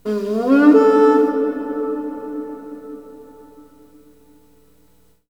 REBKHorn02.wav